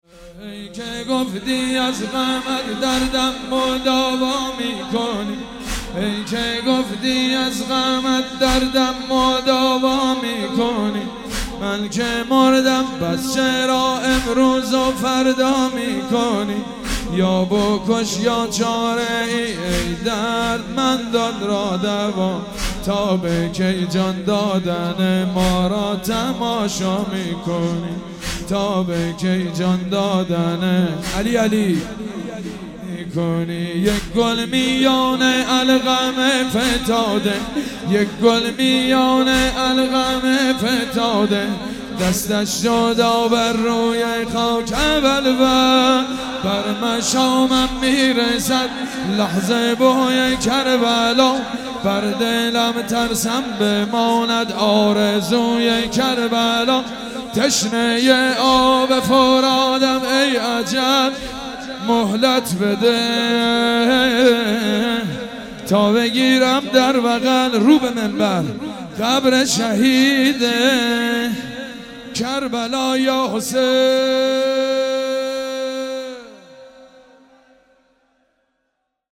مداحی حاج حسین سیب سرخی و حاج مجید بنی فاطمه در خمینی شهر